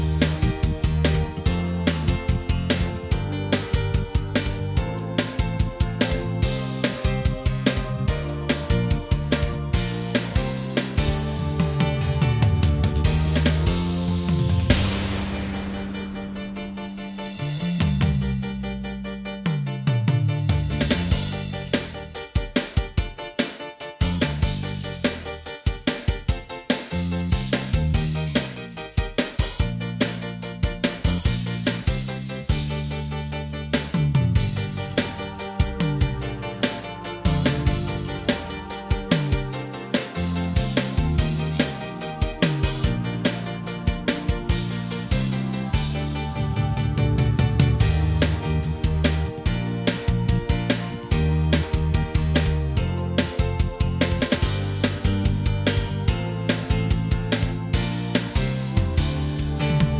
Minus Main Guitars For Guitarists 3:18 Buy £1.50